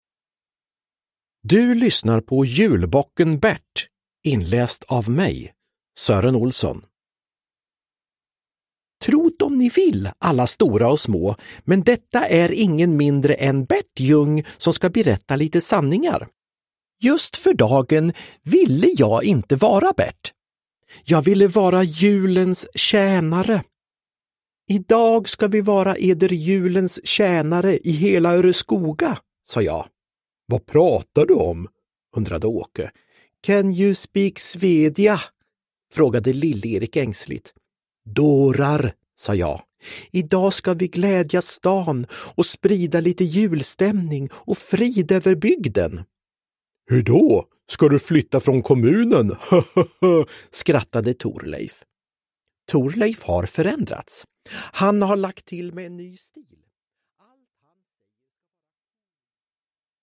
Julbocken Bert – Ljudbok – Laddas ner
Uppläsare: Anders Jacobsson, Sören Jacobsson